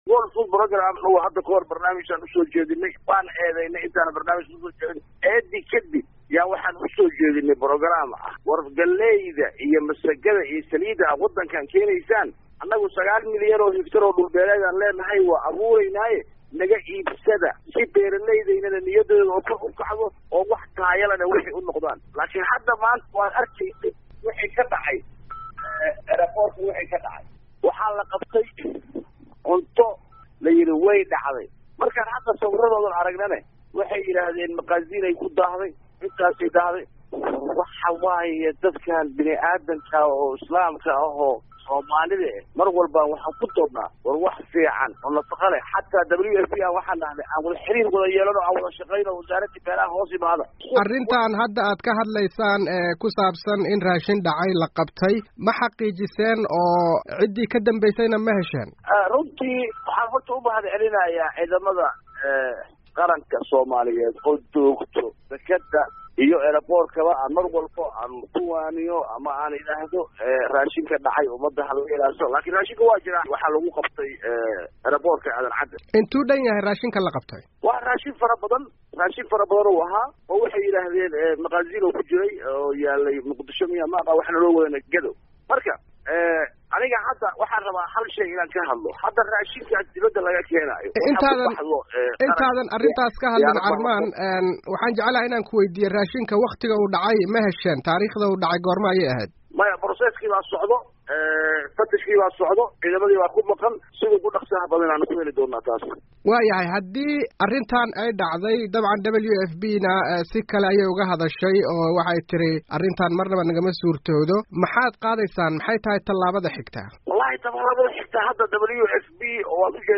Wasiir Ku-xigeenka Wasaaradda Beeraha Soomaaliya, Cabdicasiis Saalax Carmaan oo la soo xiriiray VOA –da ayaa si kulul uga hadlay raashinka dhacay ee lagu qabtay garoonka.
Wareysi: Wasiirka Beeraha Soomaaliya